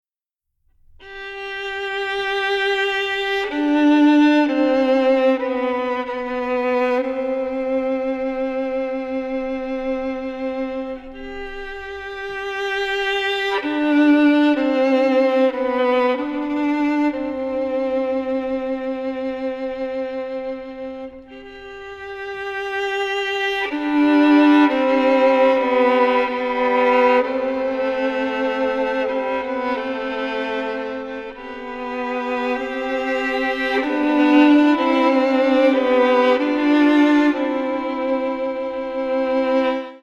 ヴァイオリン
レコーディングスタジオ : JEO